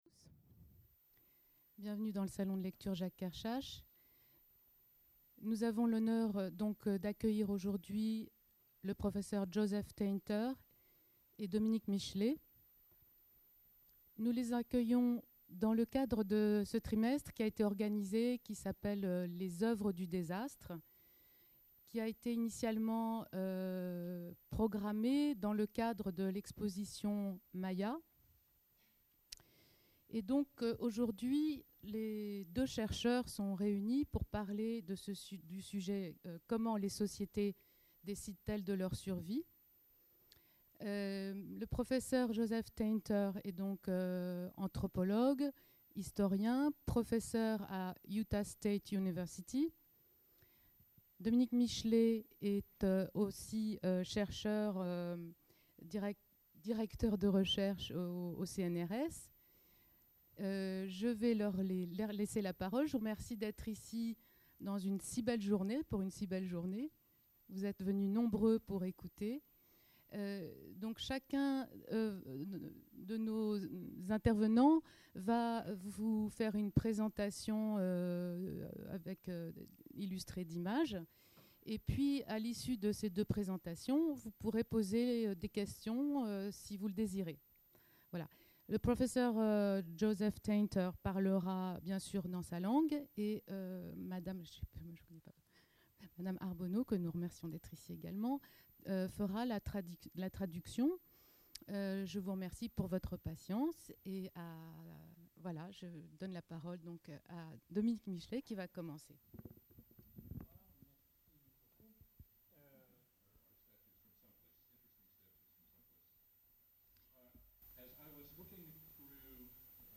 Table-ronde dans le cadre de l’exposition Maya
Conférence enregistrée au Salon de lecture Jacques Kerchache le 2 octobre 2011